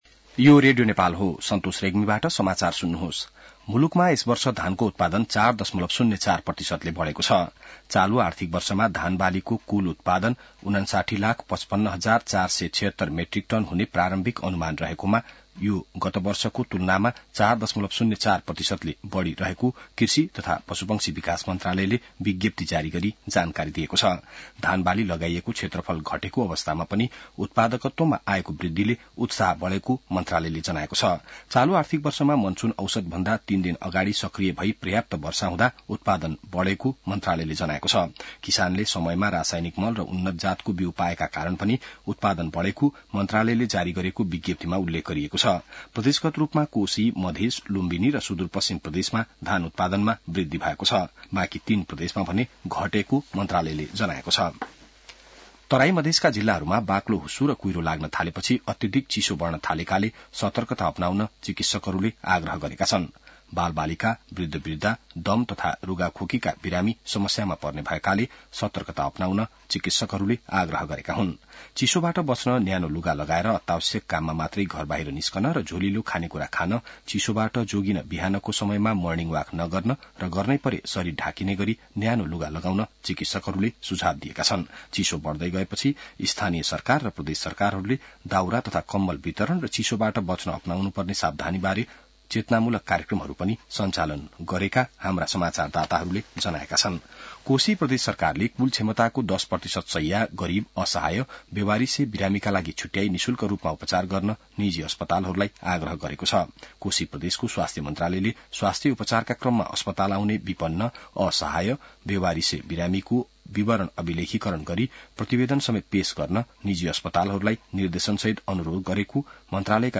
बिहान ६ बजेको नेपाली समाचार : २१ पुष , २०८१